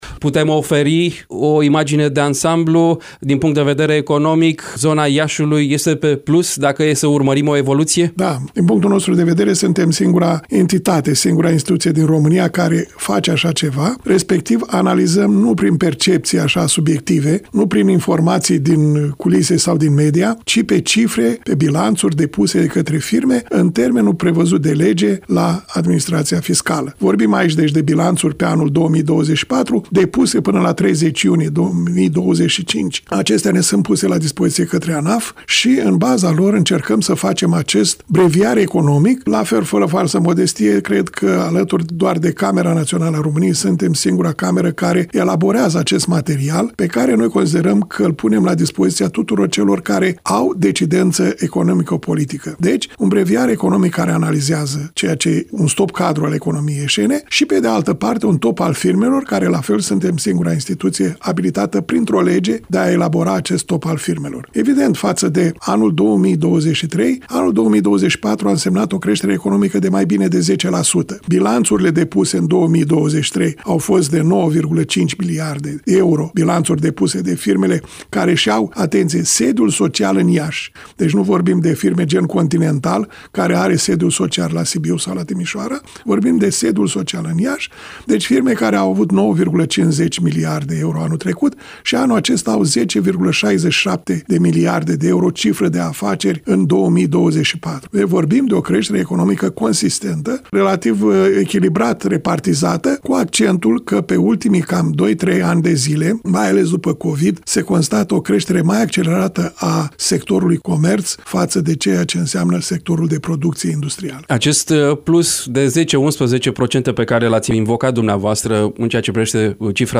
Varianta audio a interviului: Share pe Facebook Share pe Whatsapp Share pe X Etichete